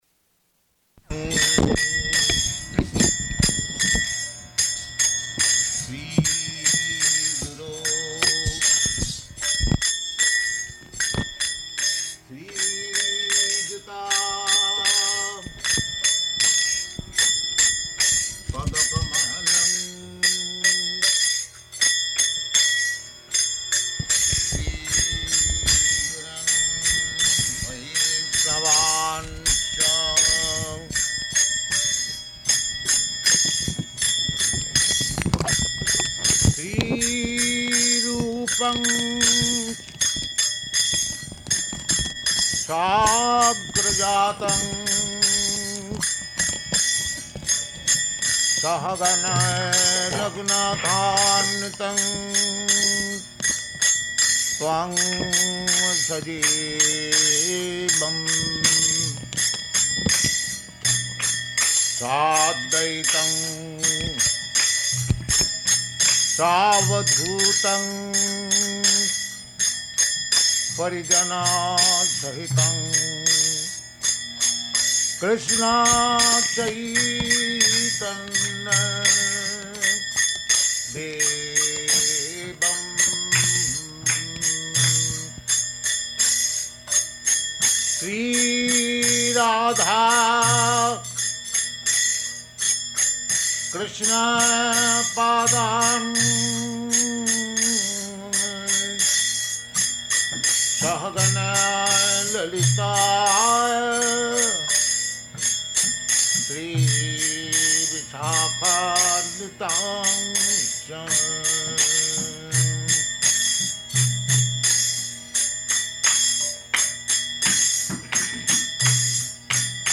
Lecture
Type: Lectures and Addresses
Location: Seattle